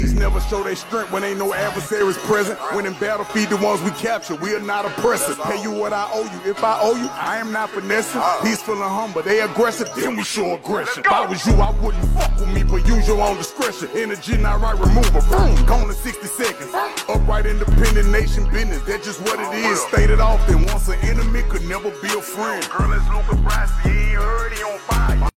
weaving in subtle synths and harmonious backing vocals